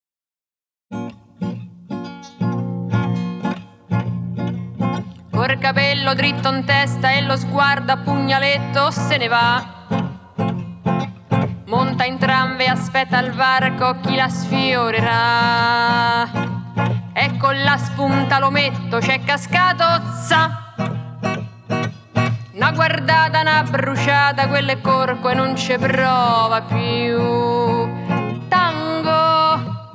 Жанр: Фолк